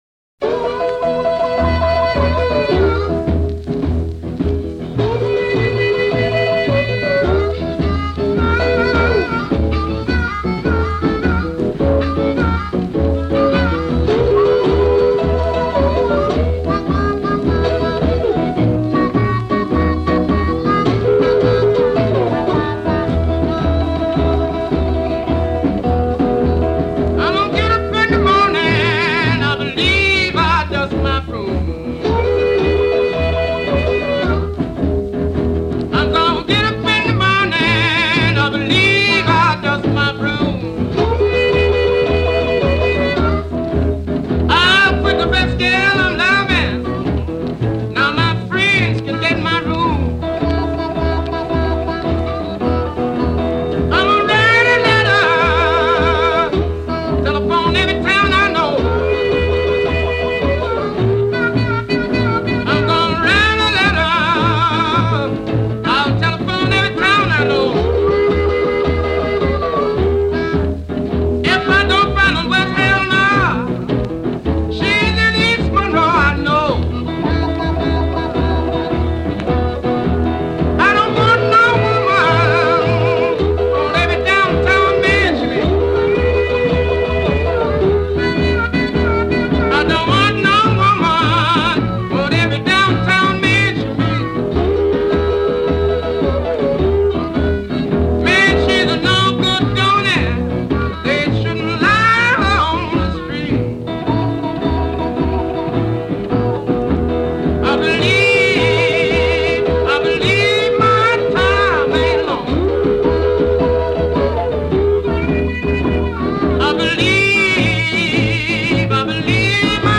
One of the great underrated bluesmen.